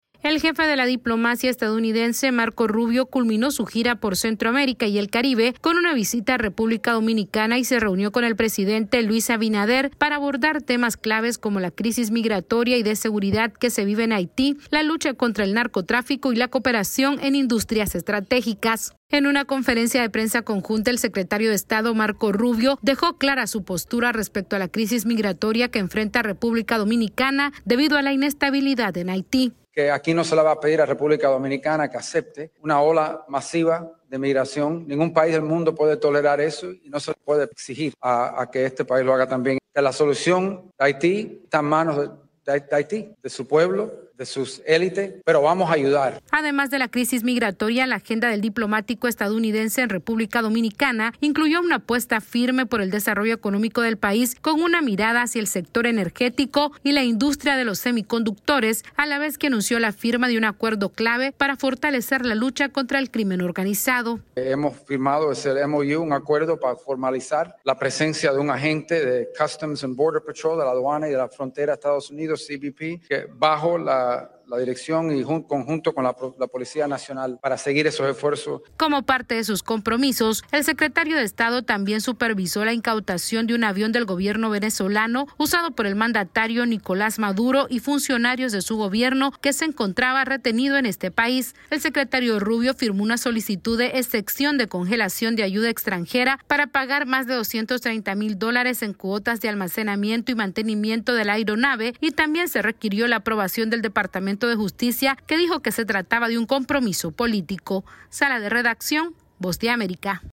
AudioNoticias
El secretario de EEUU, Marco Rubio, culminó su gira por Centroamérica y el Caribe en República Dominicana donde abordó temas clave como la lucha contra el narcotráfico, la migración y la cooperación económica. Esta es una actualización de nuestra Sala de Redacción.